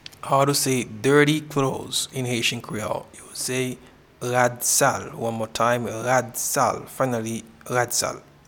Pronunciation and Transcript:
Dirty-clothes-in-Haitian-Creole-Rad-sal.mp3